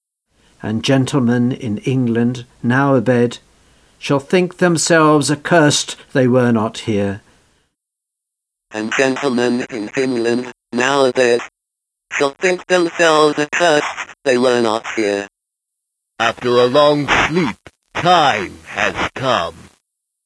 That speak-n-spell voice , is via something called an LPC codec , also the sound quality has been degraded to 8-bit …